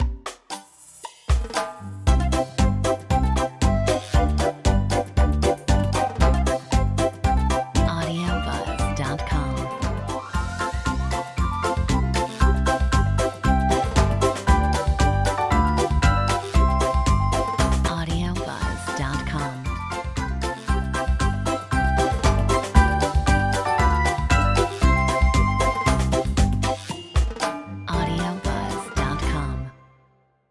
Metronome 116